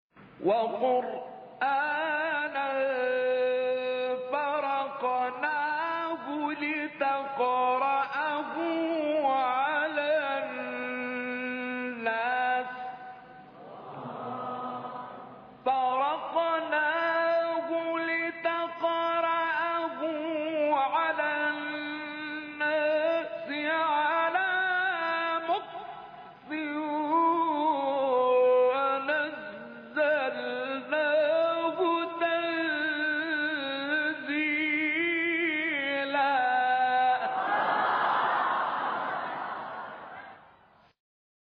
شبکه اجتماعی: مقاطع صوتی از تلاوت قاریان برجسته مصری ارائه می‌شود.